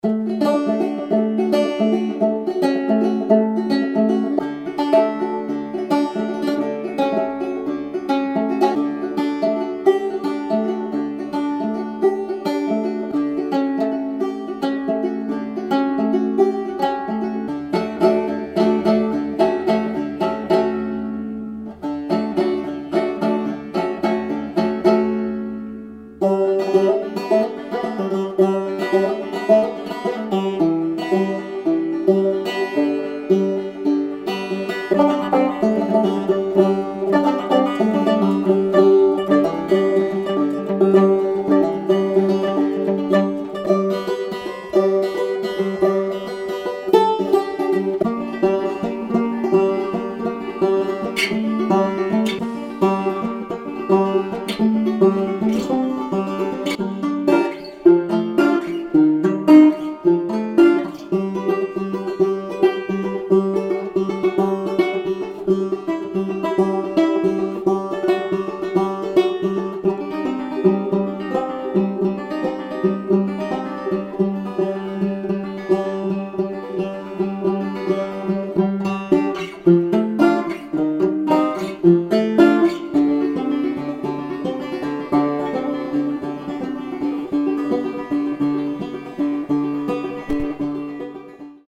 Note: this loop pack contains dry banjo loops only.